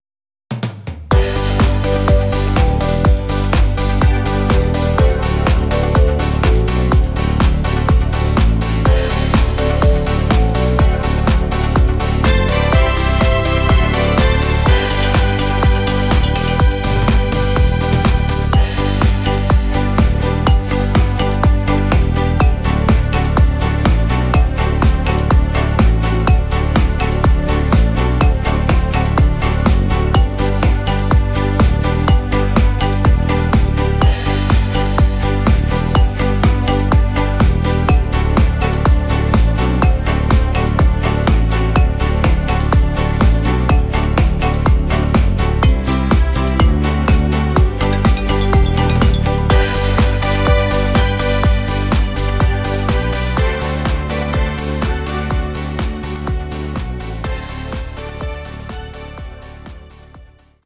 sehr melodiöser Oldie